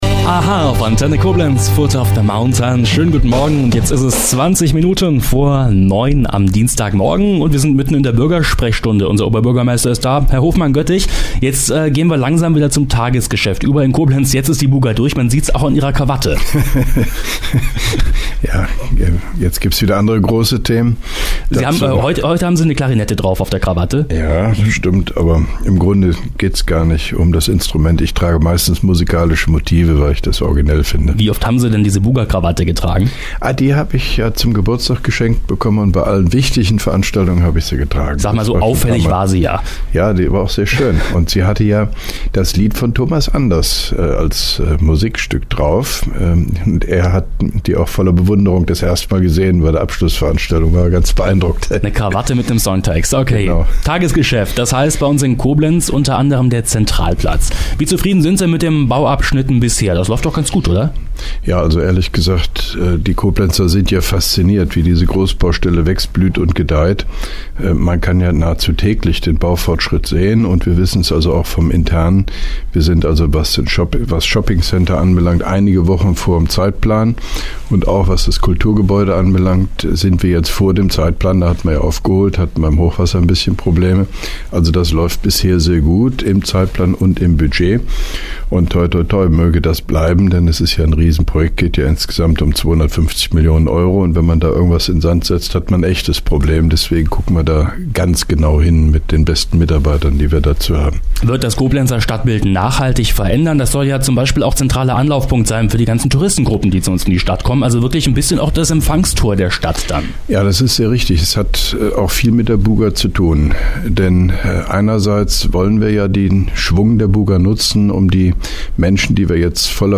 Antenne Koblenz 98,0 am 18.10.2011, ca. 8.40 Uhr (Dauer 03:56 Minuten)